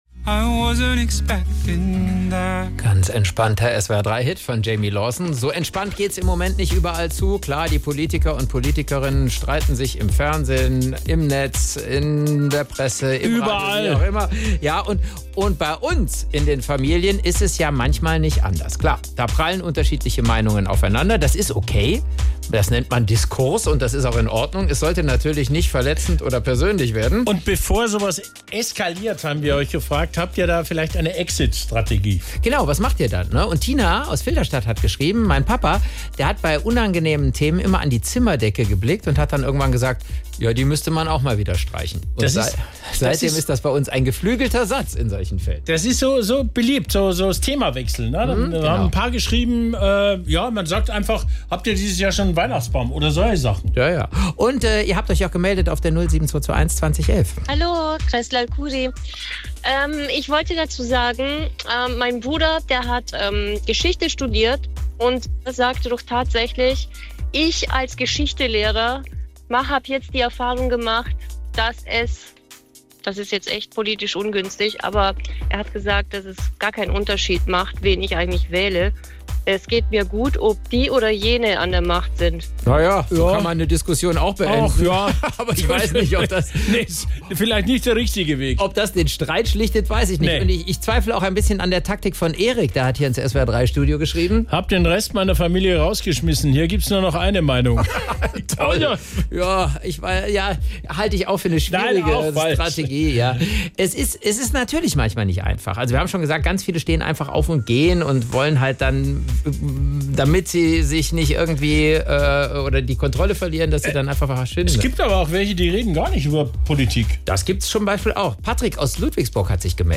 Clevere Sprüche, die ein Gespräch beenden – Best-of aus dem Radio